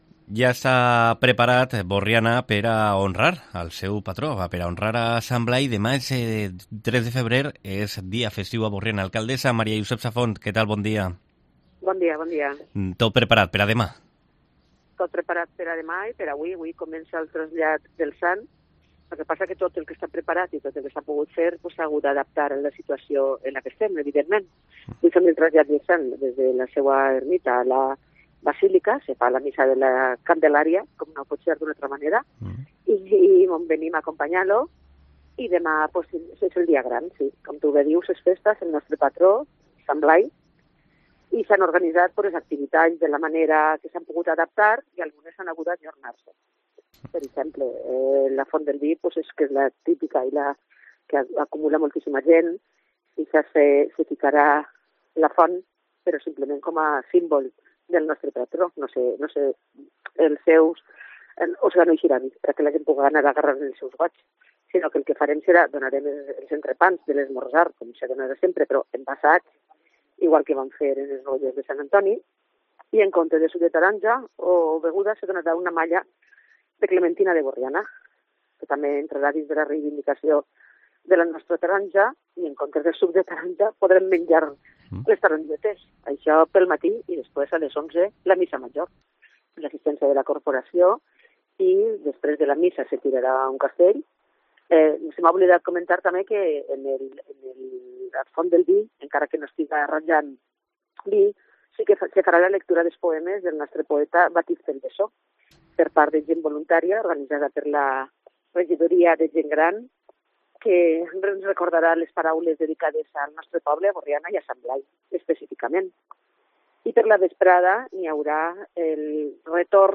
Entrevista
Burriana celebra la festividad de su patrón, Sant Blai, como explica en COPE la alcaldesa, Maria Josep Safont